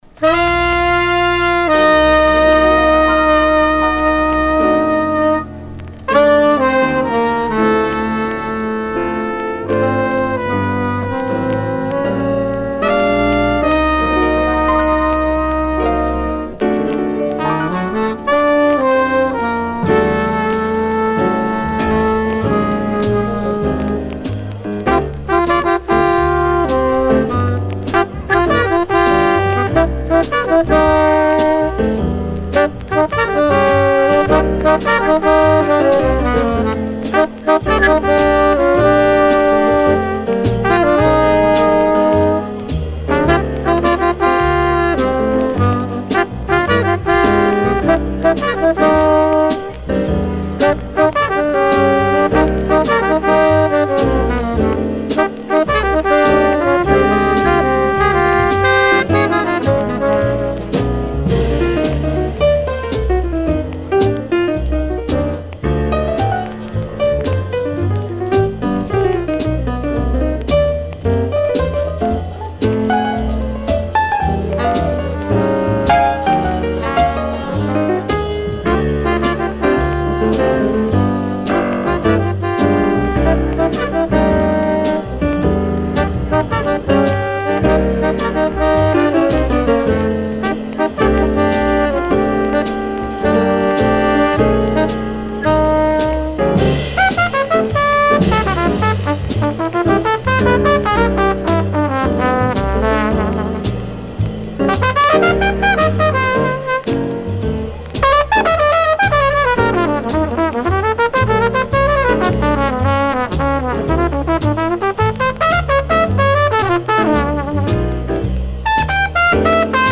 Original 1960 mono pressing
Recorded April 15, 1959 at Birdland NYC